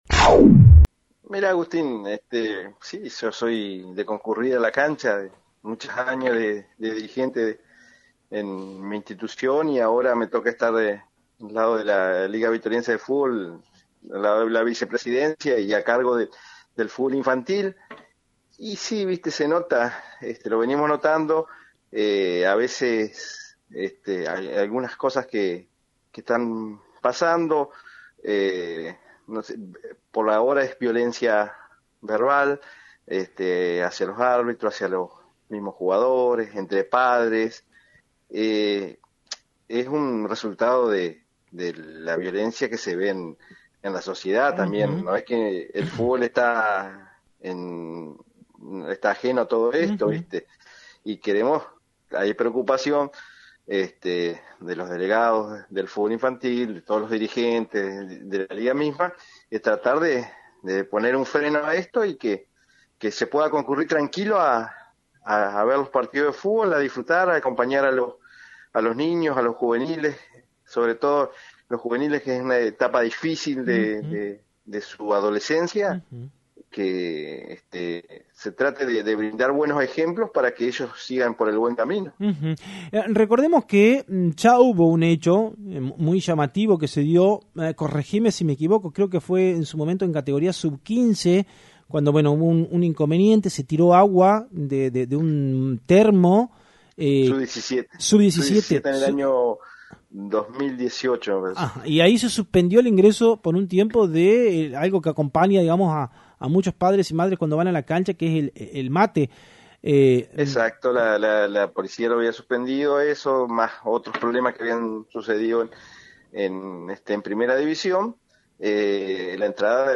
En diálogo con FM 90.3